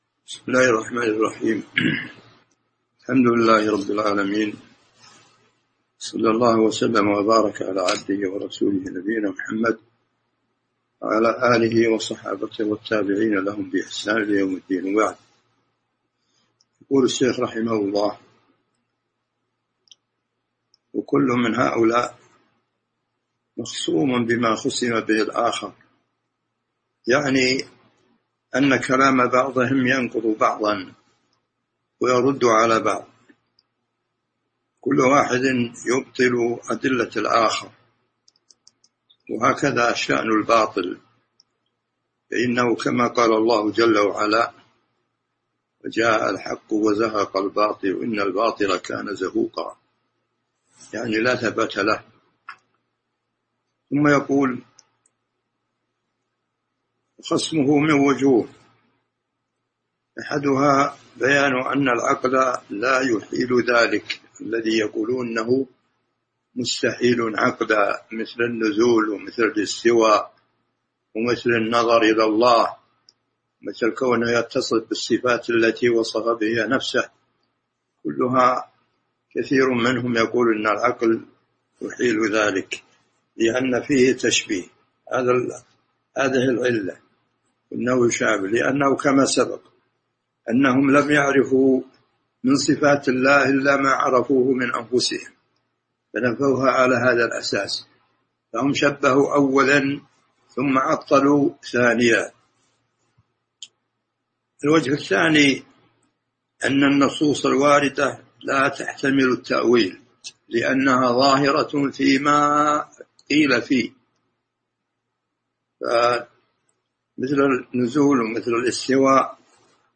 تاريخ النشر ٤ ذو القعدة ١٤٤٢ هـ المكان: المسجد النبوي الشيخ